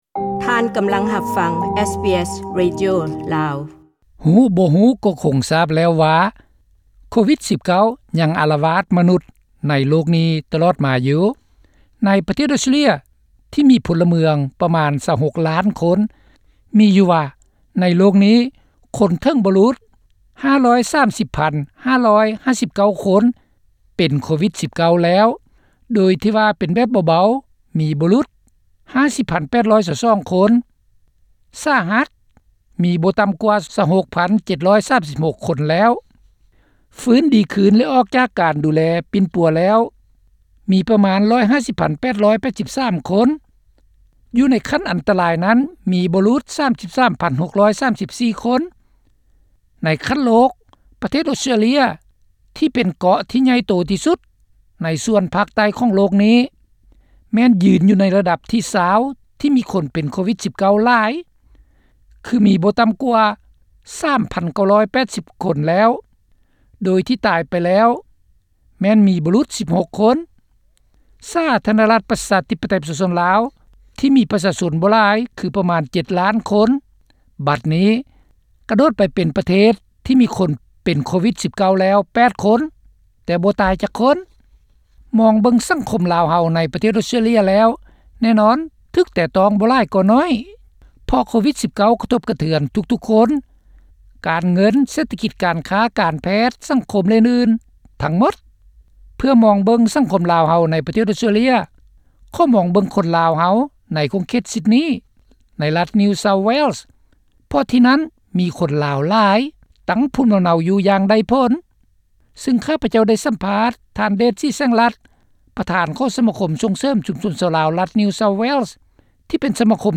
ສຳພາດ : ສະຖານະພາບ ໂກວິດ-19 ໃນຊຸມຊົນລາວໃນອອສເຕຼລັຍ